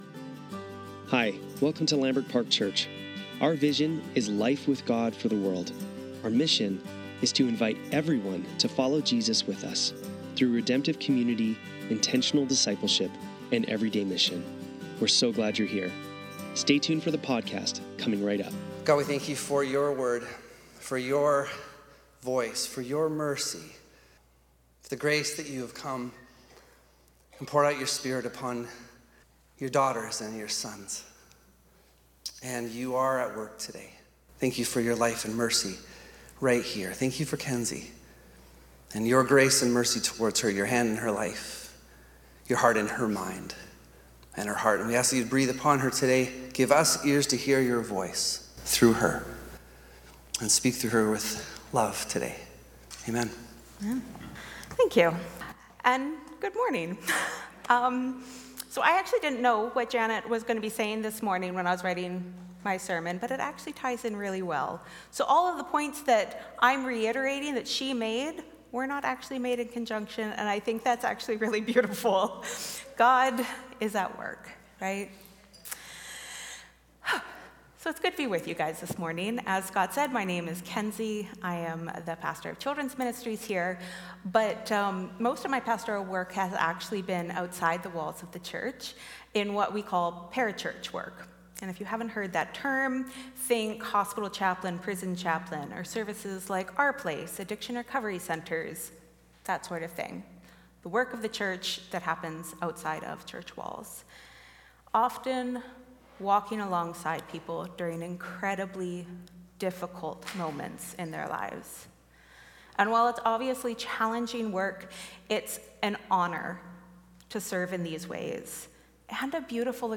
Sunday Service - September 1, 2024